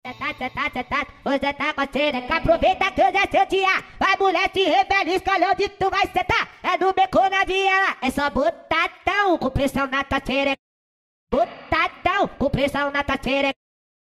Acapella de Funk